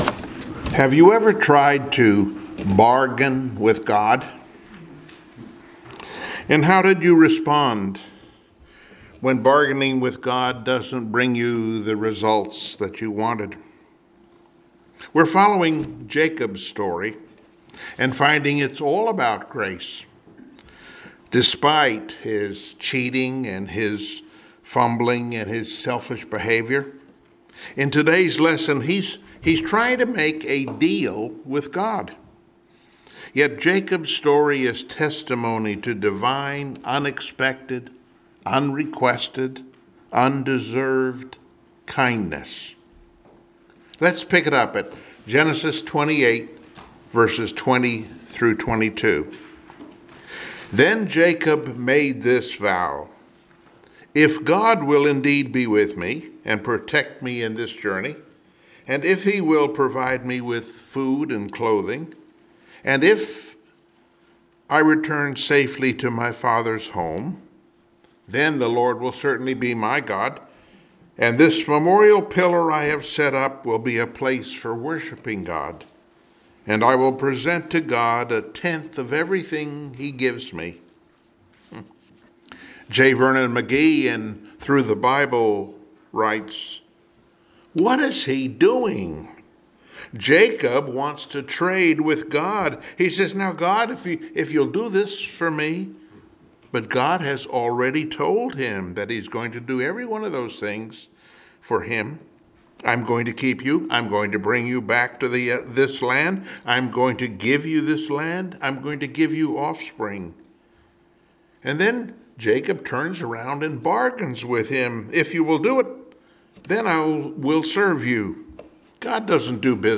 Passage: Genesis 28:20-22 Service Type: Sunday Morning Worship Topics